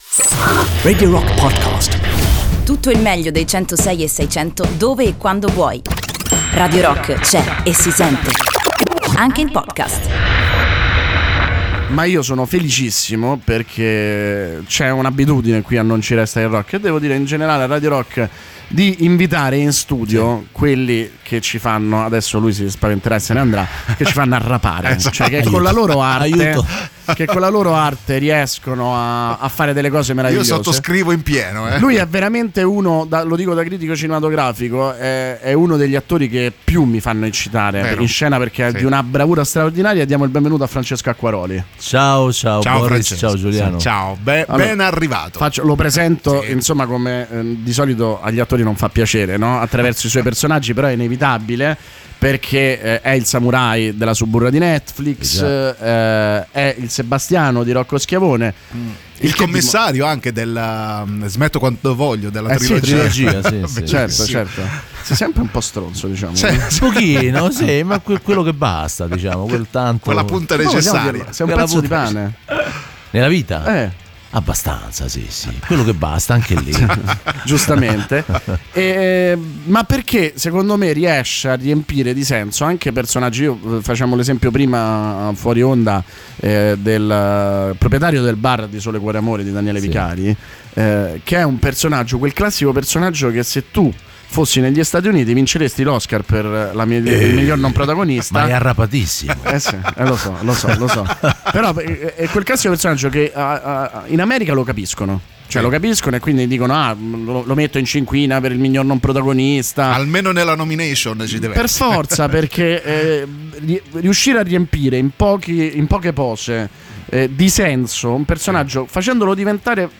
Intervista: Francesco Acquaroli (07-01-19)